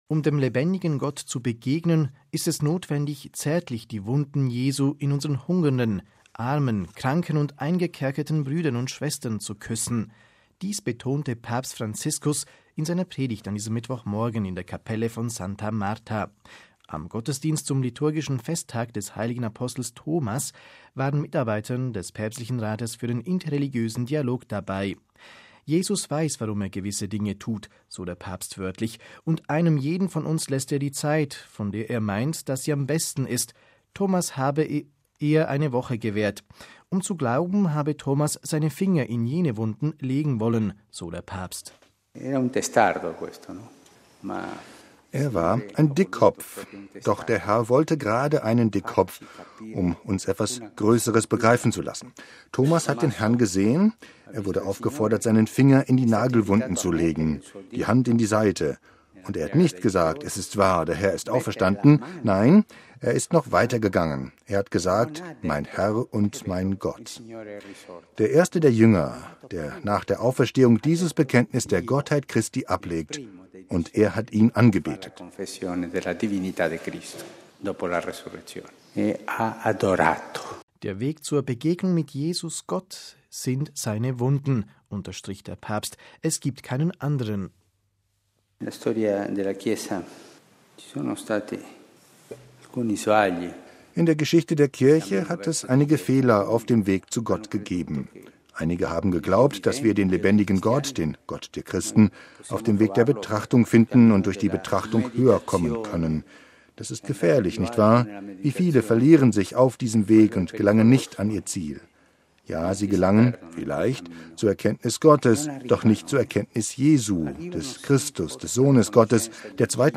Papstpredigt: Gott trifft man bei den Armen
MP3 Um dem lebendigen Gott zu begegnen, ist es notwendig, zärtlich die Wunden Jesu in unseren hungernden, armen, kranken und eingekerkerten Brüdern und Schwestern zu küssen. Dies betonte Papst Franziskus in seiner Predigt an diesem Mittwochmorgen in der Kapelle von Santa Marta.